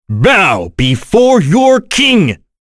Kain-Vox_Victory_b.wav